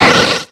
Cri de Lilia dans Pokémon X et Y.